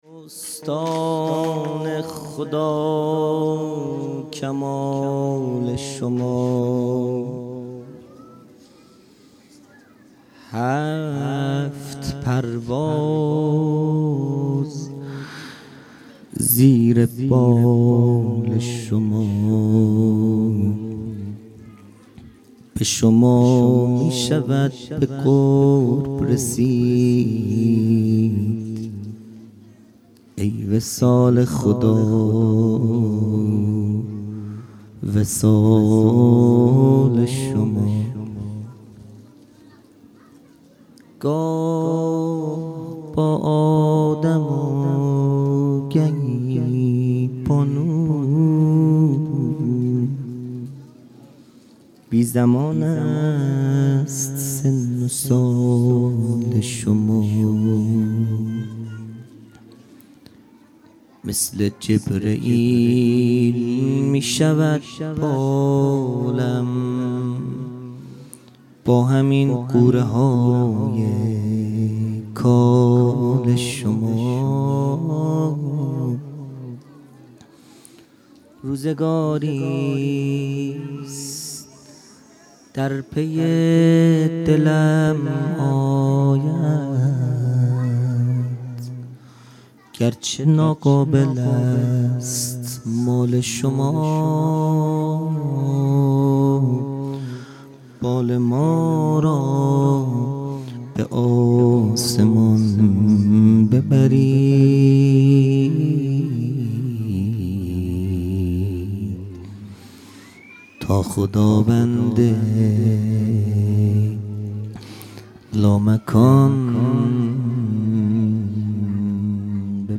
خیمه گاه - هیئت بچه های فاطمه (س) - مدح پایانی | داستان خدا کمال شما